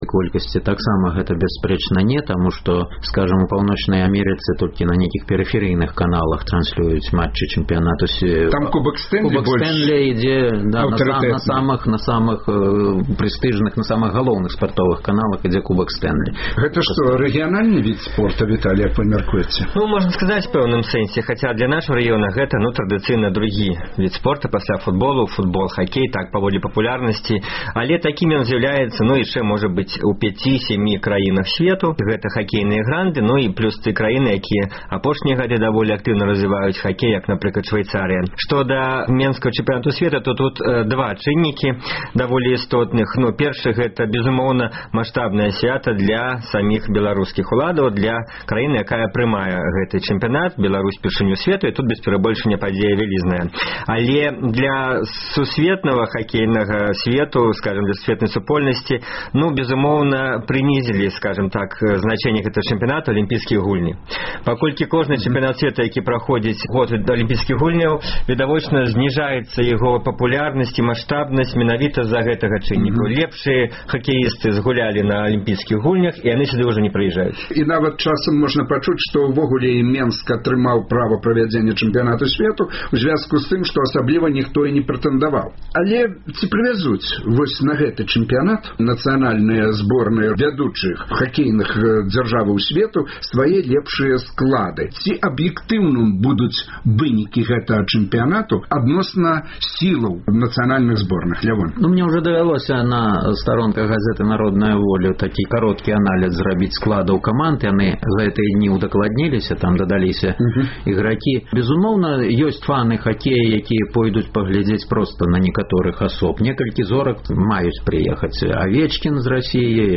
Каманды якіх краінаў будуць весьці рэй на турніры і якія шанцы ў беларускай зборнай? У дыскусіі бяруць удзел пісьменьнік